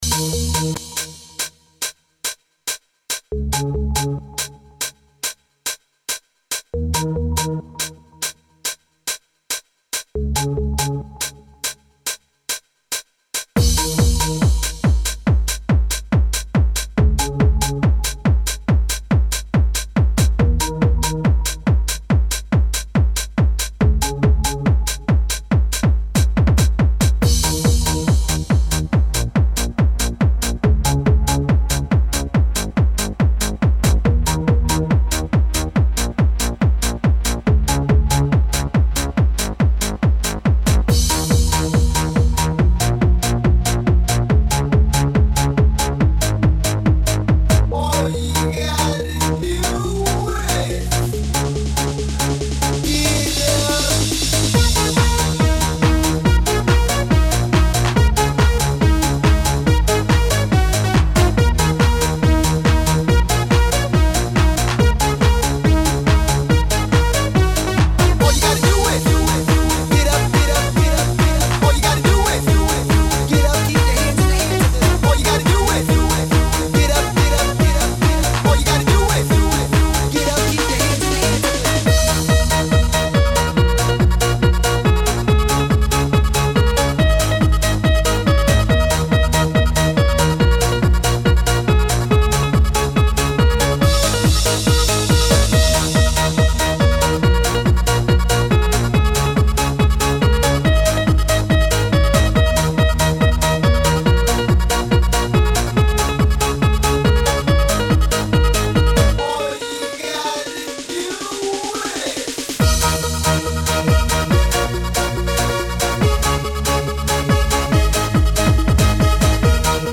Genre: Eurodance.